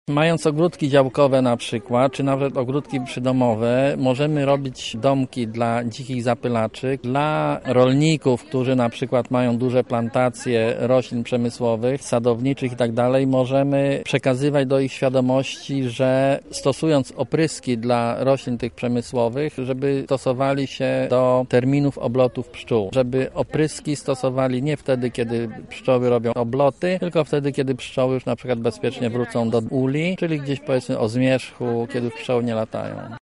W Ogrodzie Botanicznym UMCS odbyły się coroczne obchody „Święta Pszczół”.